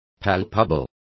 Complete with pronunciation of the translation of palpable.